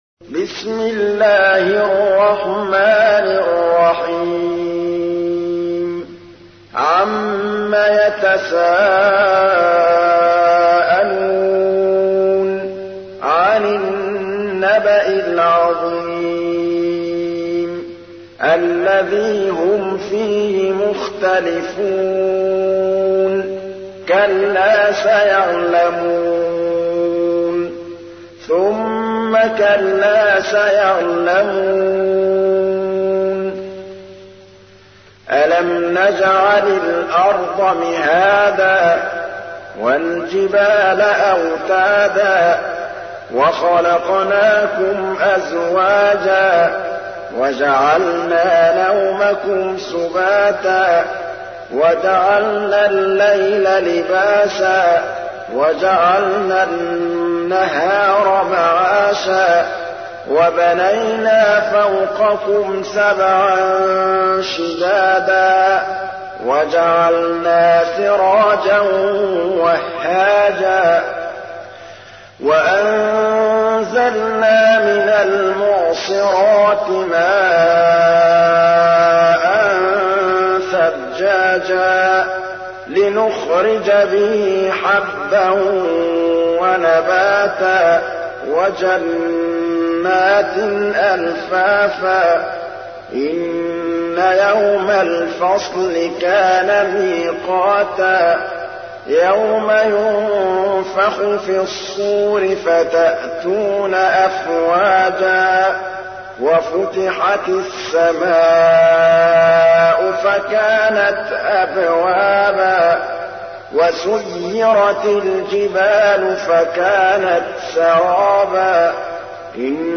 تحميل : 78. سورة النبأ / القارئ محمود الطبلاوي / القرآن الكريم / موقع يا حسين